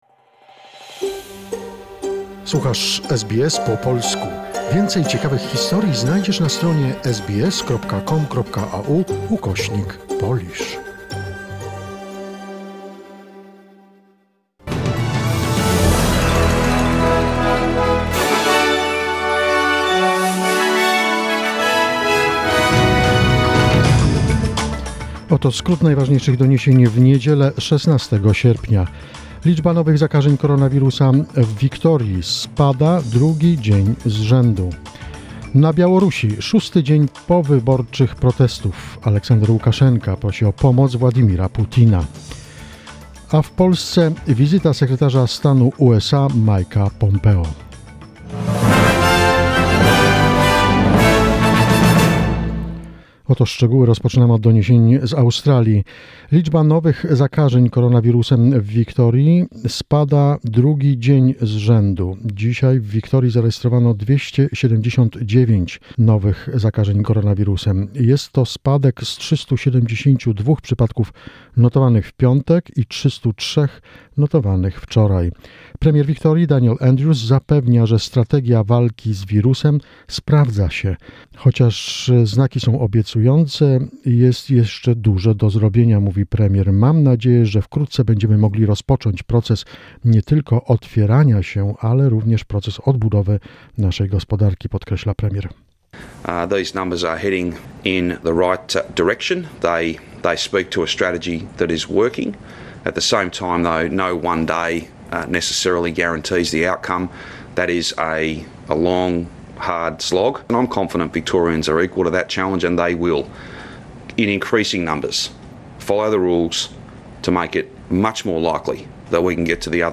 SBS News, 16 August 2020